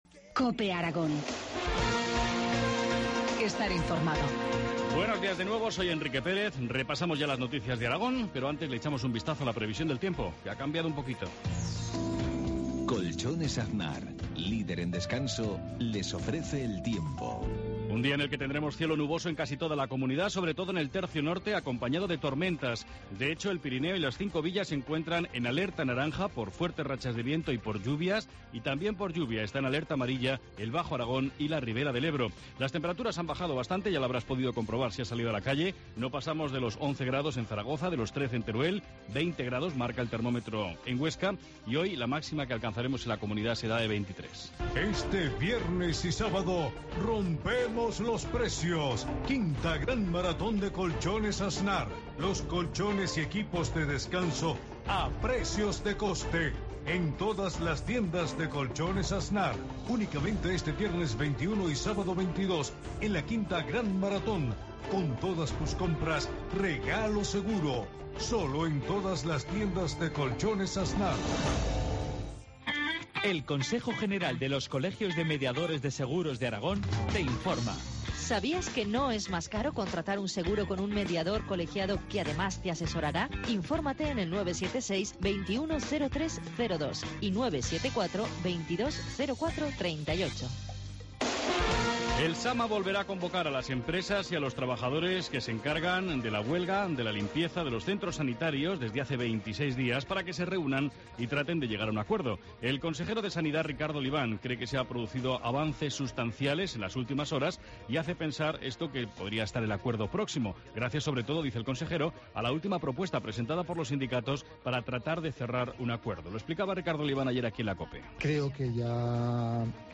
Informativo matinal, martes 18 de junio, 7.53 horas